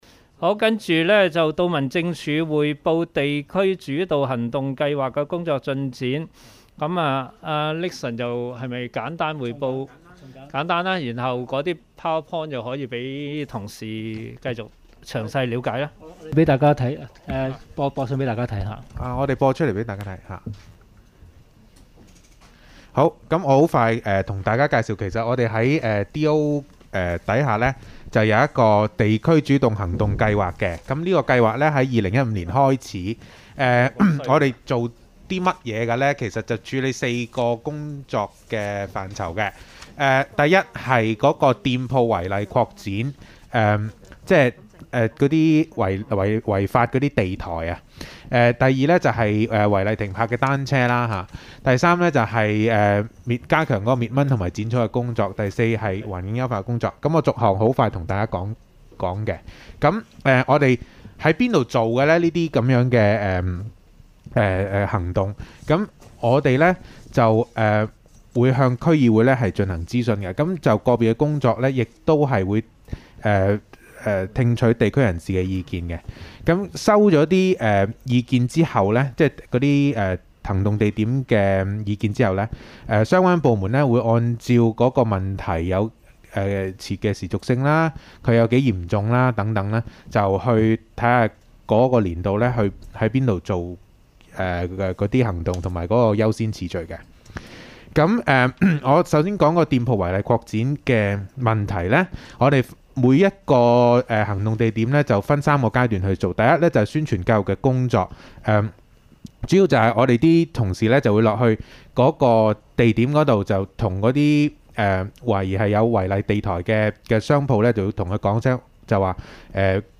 區議會大會的錄音記錄
地點: 元朗橋樂坊2號元朗政府合署十三樓會議廳